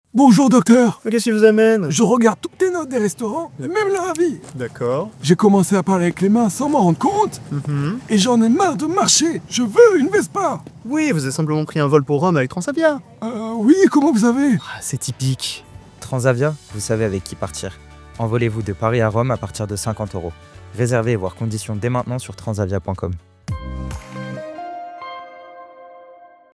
La radio diagnostique.
Silence + analyse